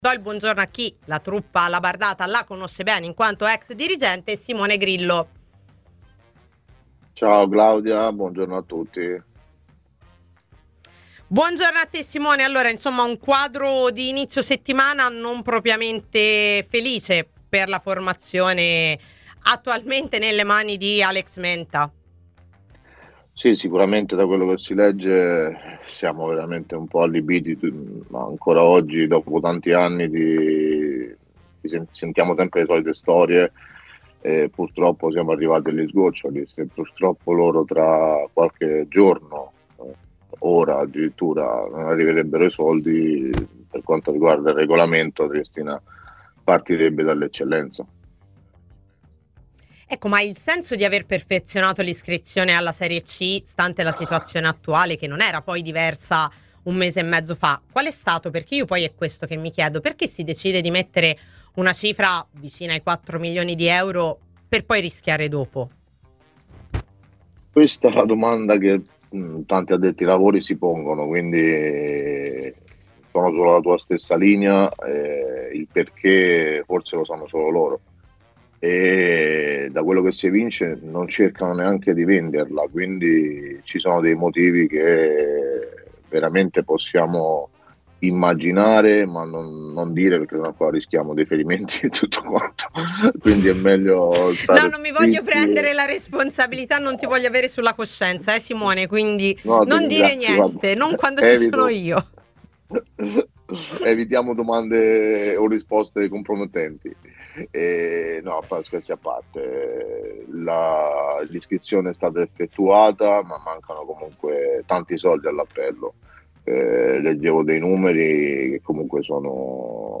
Un momento sempre più complesso quello che sta vivendo la Triestina, con il club che - senza allenatore - ha deciso di slittare ulteriormente il ritiro pre campionato, che dovrebbe partire, con tutte le incognite del caso, venerdì 25 luglio: ma niente è certo, neppure l'arrivo dei soldi necessari per proseguire con il campionato di Serie C, che vede gli alabardati regolarmente iscritti.